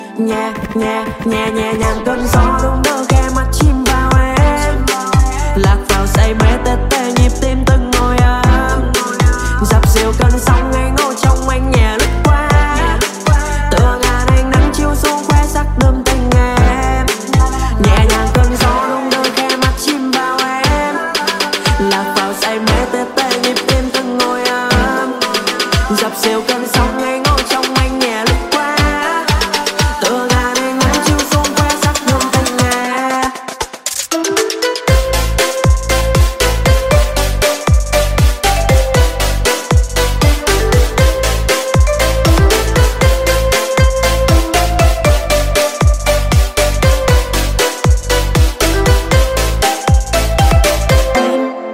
Thể loại nhạc chuông: Nhạc Tik Tok